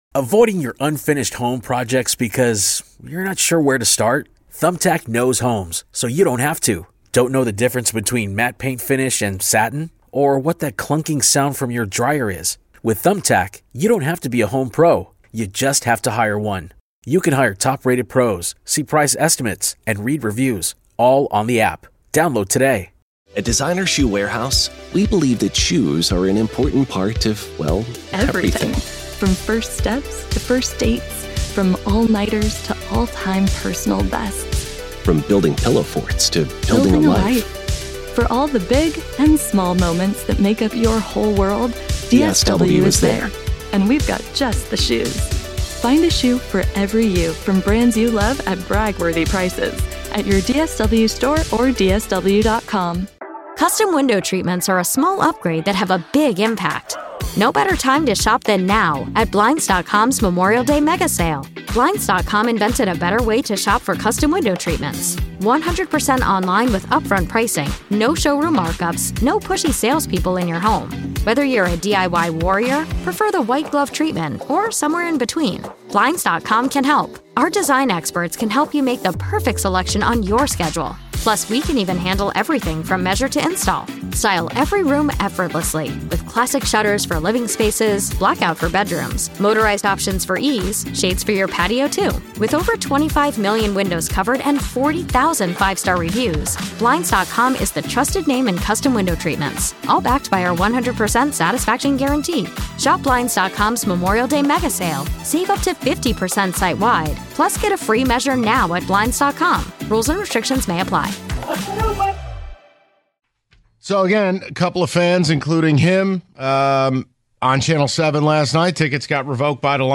The Guys Get Calls From Fans Who've Had Lions Season Tickets Revoked